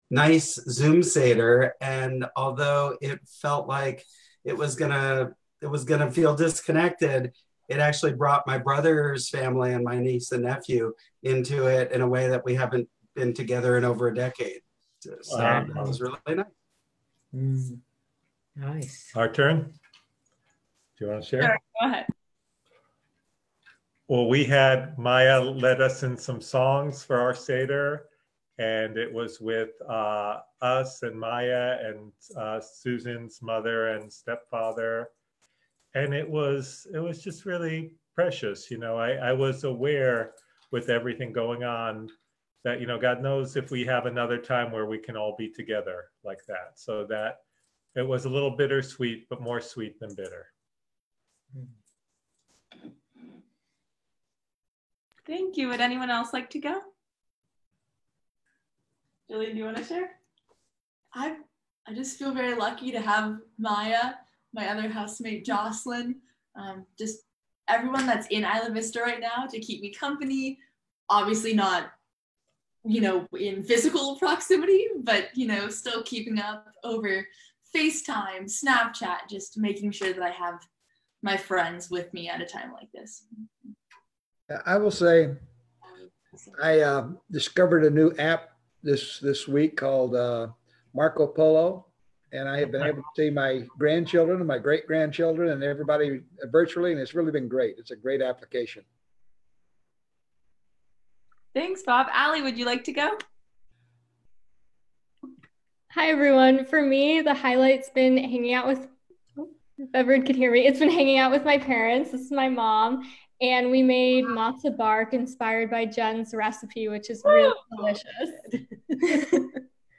Santa Barbara Hillel April 10 Shabbat Facebook Livestream (untitled)